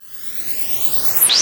FlyEngine4.wav